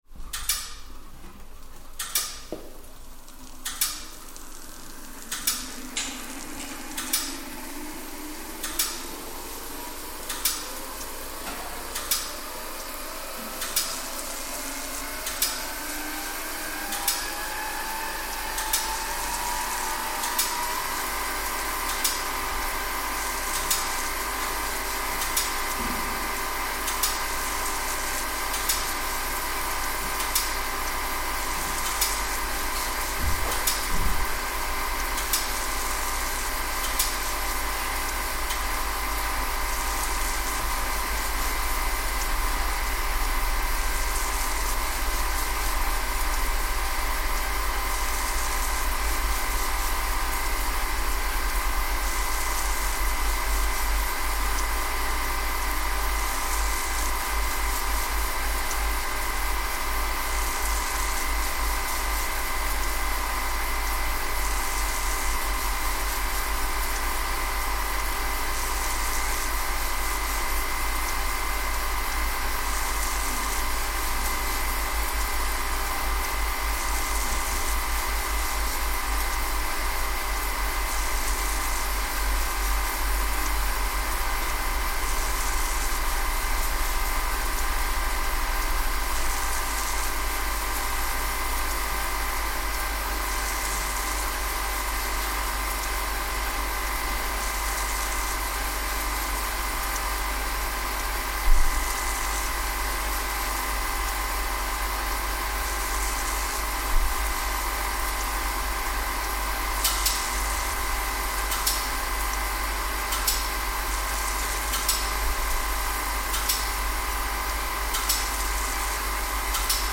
Boot and running sequence of the Heath Robinson. Year of manufacture: 1943.